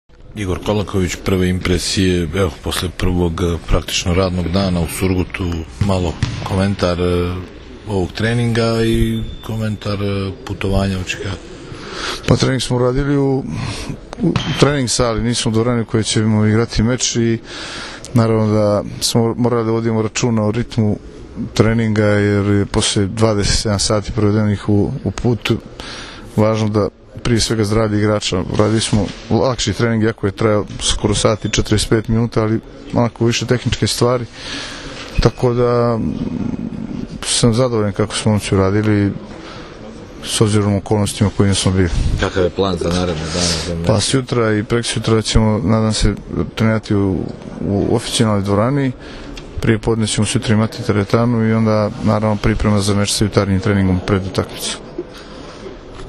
Pogača i so u rukama devojaka obučenih u rusku narodnu nošnju dočekali su, posle dugog puta iz Čikaga, seniore Srbije, uz TV ekipe koje su čekale da intervjuišu reprezentativce Srbije i članove stručnog štaba.
IZJAVA IGORA KOLAKOVIĆA